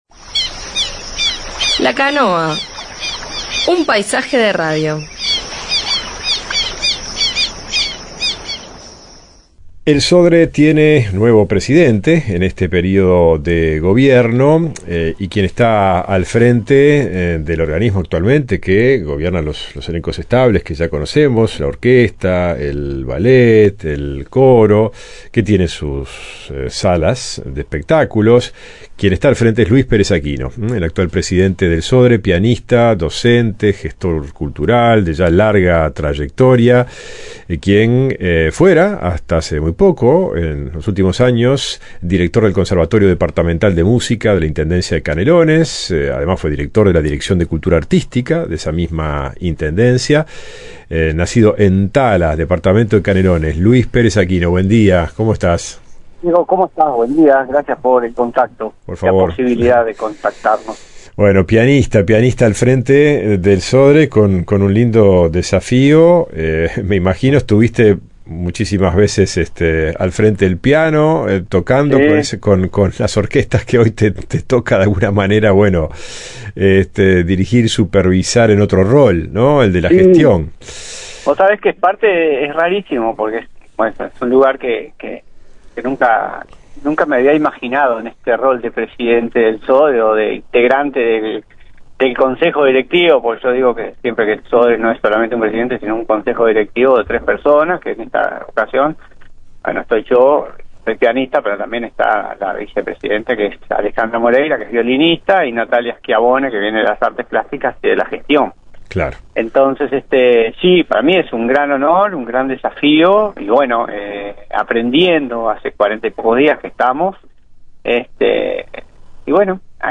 Recientemente fue nombrado Presidente del Sodre, y sobre este nuevo rol charló en La Canoa.